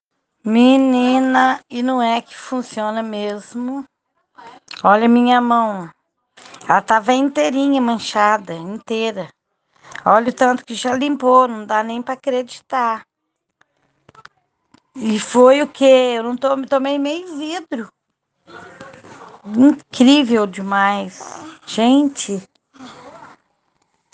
Depoimentos de quem usa: